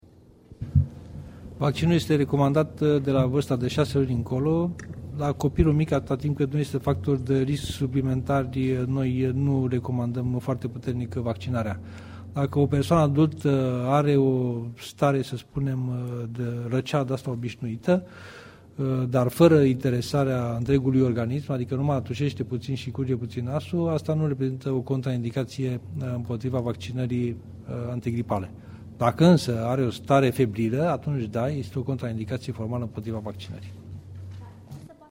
Profesor doctor Adrian Streinu Cercel – cand e recomandata vaccinarea: